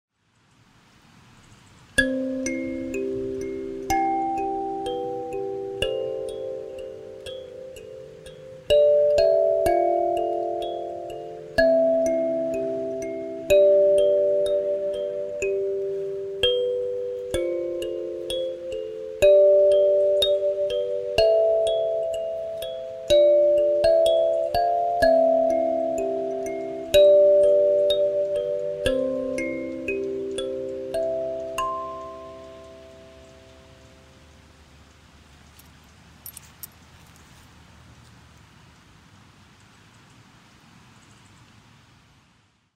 kalimba.mp3